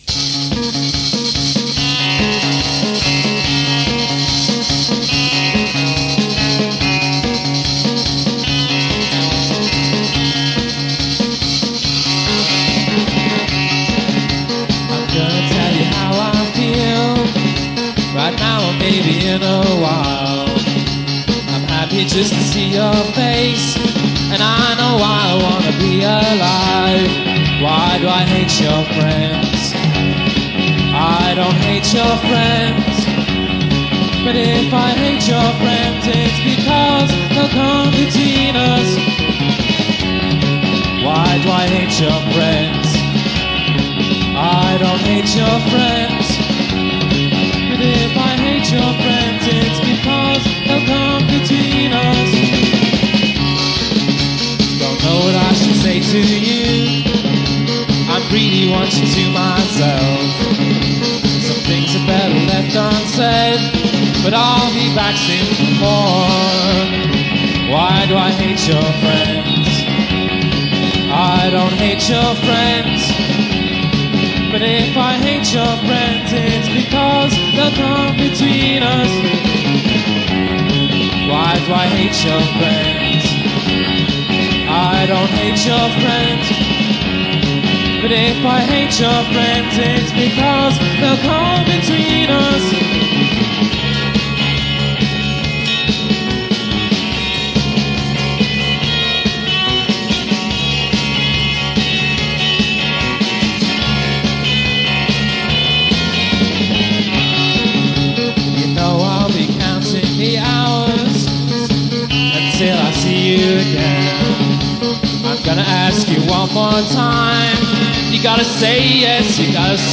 singer-bassist
guitarist
drummer